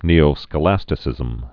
(nēō-skə-lăstĭ-sĭzəm)